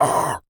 Male_Grunt_Hit_16.wav